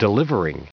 Prononciation du mot delivering en anglais (fichier audio)
Prononciation du mot : delivering